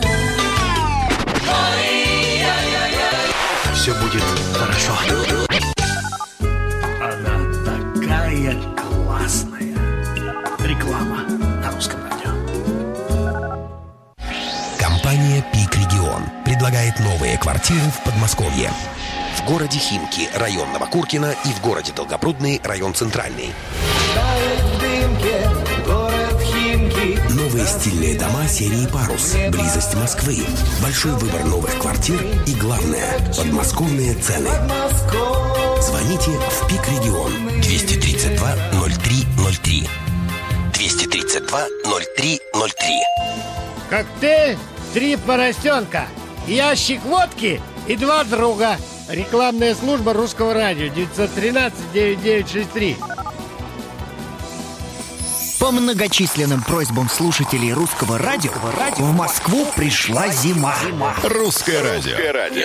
Рекламный блок (Русское радио, 05.02.2007) Квартиры в Химках